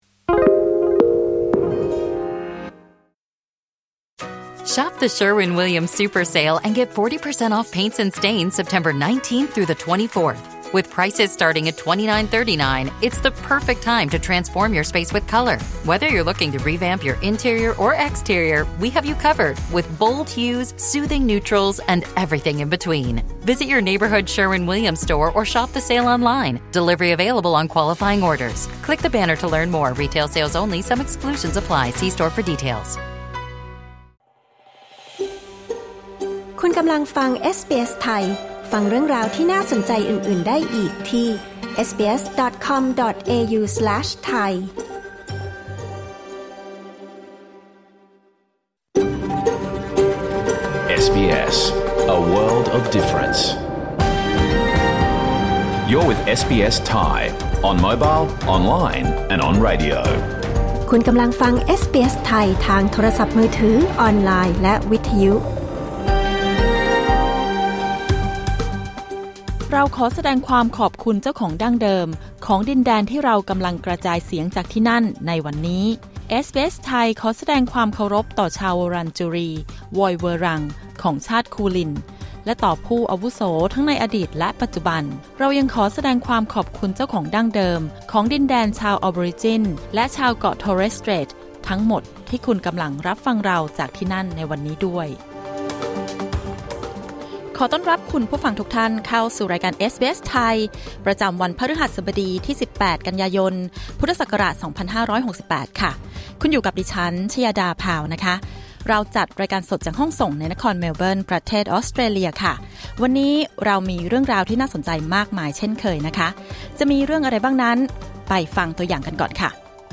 รายการสด 18 กันยายน 2568